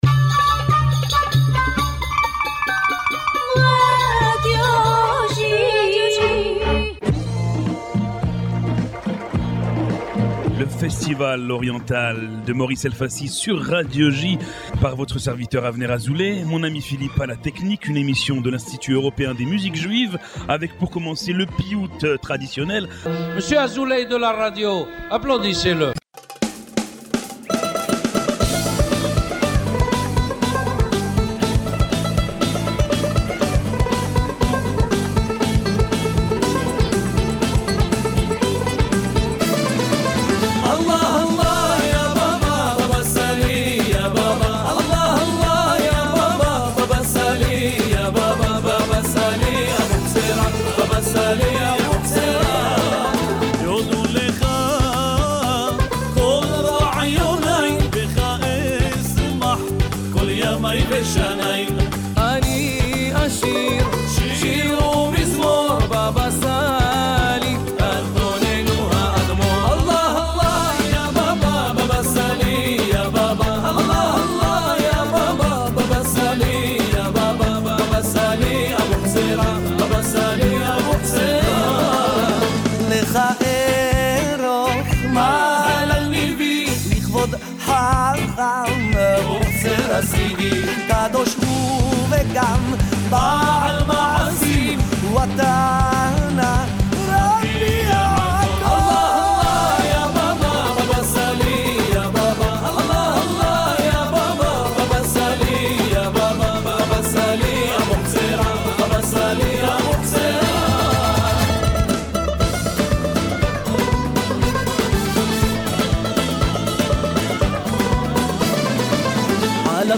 Diffusé chaque lundi sur les ondes de Radio J (94.8 FM), Le festival oriental est une émission de l’Institut Européen des Musiques Juives entièrement dédiée à la musique orientale.